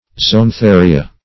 Zoantharia - definition of Zoantharia - synonyms, pronunciation, spelling from Free Dictionary Search Result for " zoantharia" : The Collaborative International Dictionary of English v.0.48: Zoantharia \Zo`an*tha"ri*a\, n. pl.
zoantharia.mp3